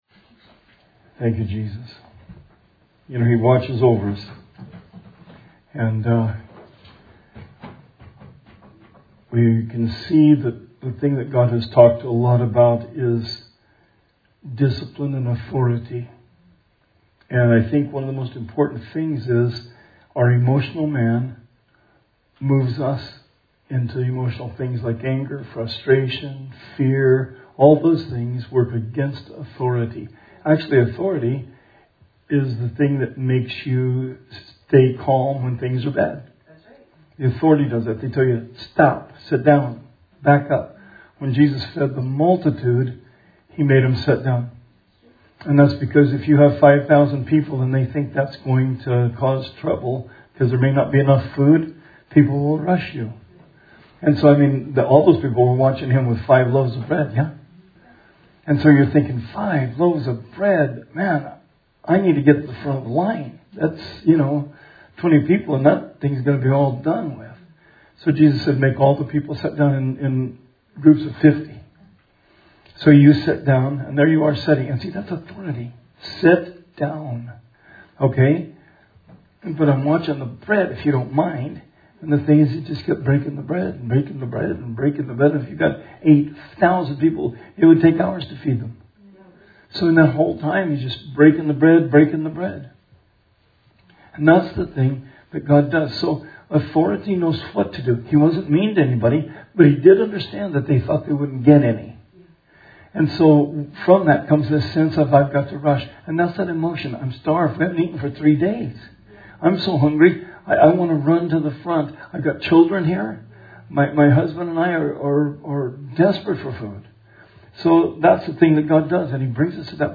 Bible Study 6/10/20